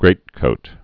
(grātkōt)